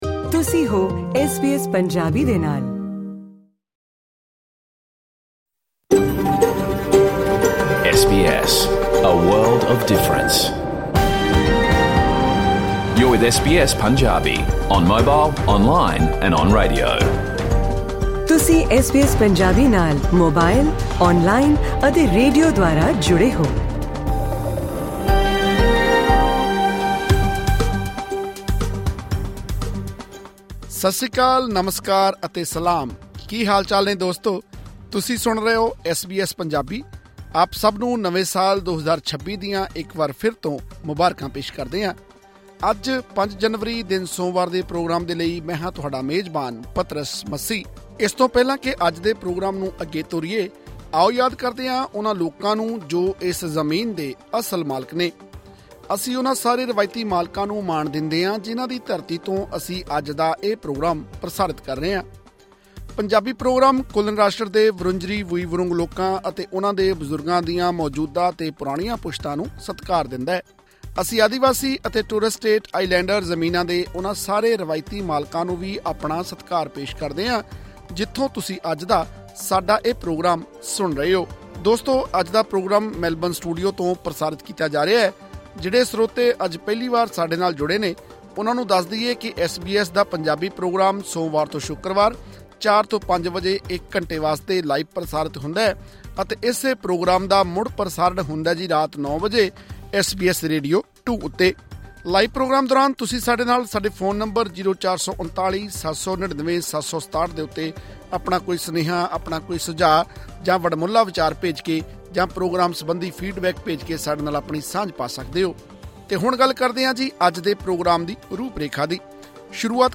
ਐਸ ਬੀ ਐਸ ਪੰਜਾਬੀ ਦਾ ਰੇਡੀਓ ਪ੍ਰੋਗਰਾਮ ਸੋਮਵਾਰ ਤੋਂ ਸ਼ੁੱਕਰਵਾਰ ਸ਼ਾਮ 4 ਵਜੇ ਤੋਂ 5 ਵਜੇ ਤੱਕ ਲਾਈਵ ਪ੍ਰਸਾਰਿਤ ਹੁੰਦਾ ਹੈ।